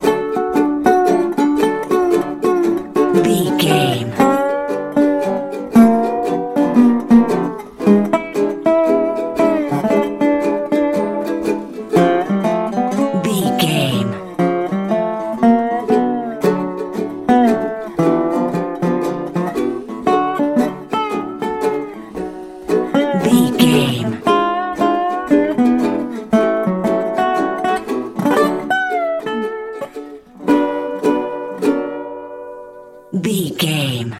Uplifting
Ionian/Major
D
acoustic guitar
bass guitar
ukulele
slack key guitar